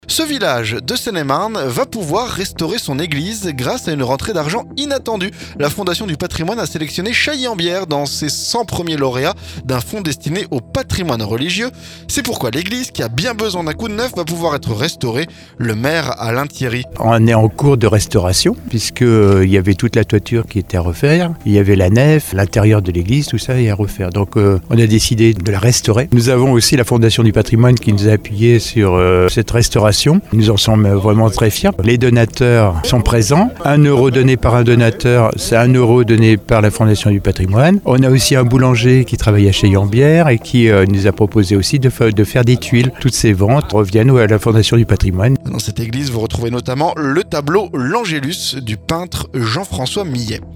On en parle avec le maire, Alain Thierry.